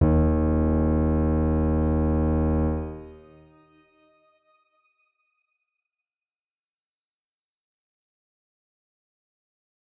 X_Grain-D#1-pp.wav